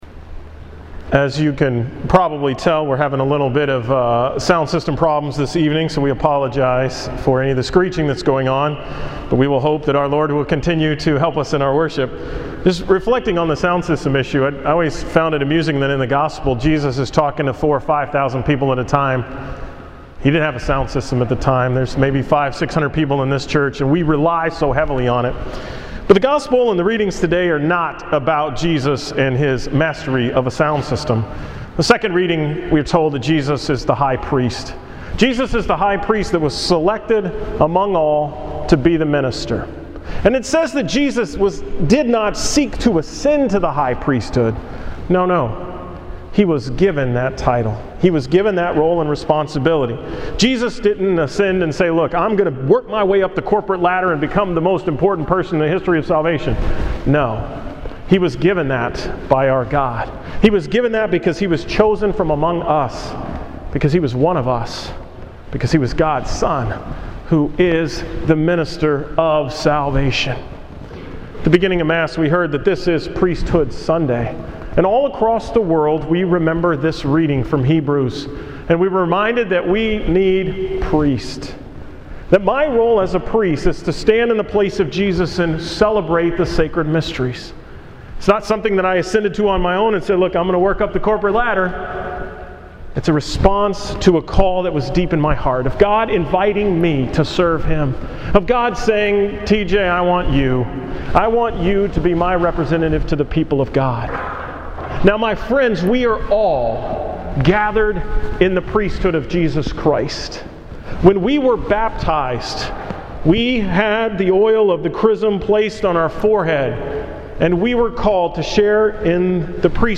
From the Saturday evening Mass at St. Mary’s on the 30th Sunday in Ordinary Time:
Category: 2012 Homilies | Tags: ,